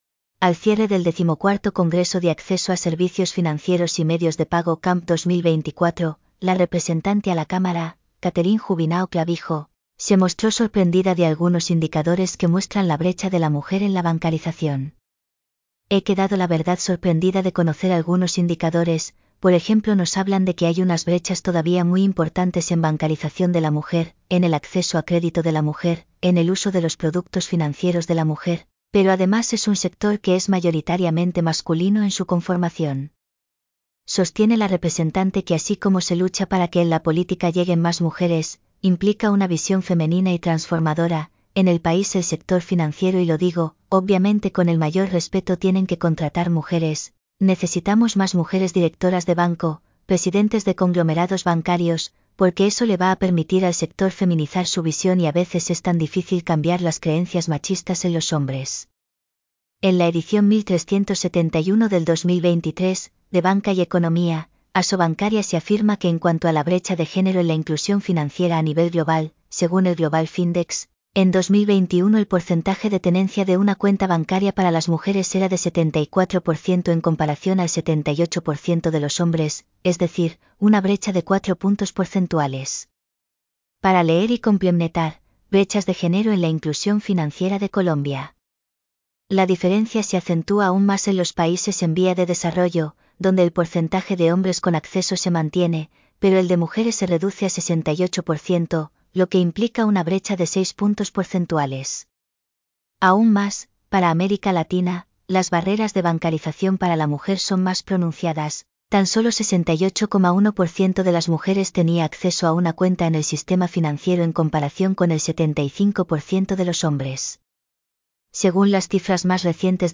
Text-to-Speech-_1_.mp3